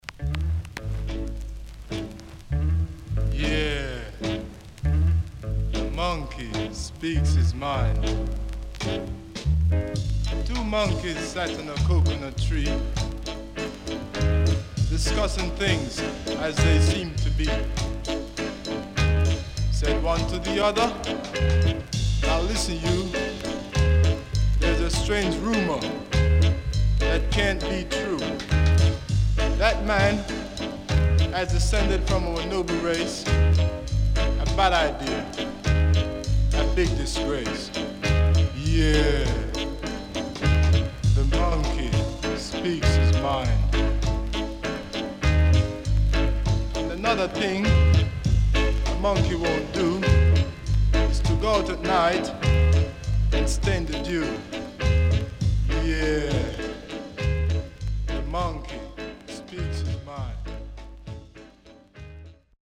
Nice Rocksteady Vocal.Pressnoise
SIDE A:軽くプレスノイズありますが良好です。